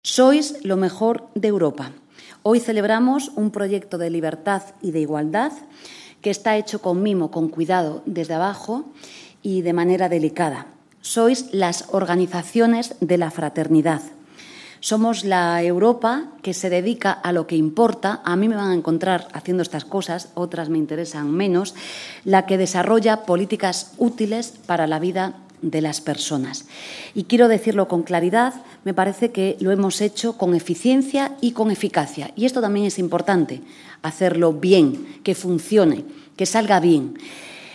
Esta es una de las principales conclusiones de la jornada ‘Más que empleo’, celebrada el 17 de mayo en el salón de actos del Ministerio de Trabajo y Economía Social, con presencia de la ministra de Trabajo y vicepresidenta del Gobierno, Yolanda Díaz, en la que se presentaron los resultados de las acciones desarrolladas en el periodo 2016-2023 así como los retos de futuro hasta 2027.